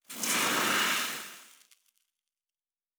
beyond/Assets/Sounds/Fantasy Interface Sounds/Blacksmith 08.wav at bbce956e7ca28af9cbd18ea01cc1d30d488ddf92
Blacksmith 08.wav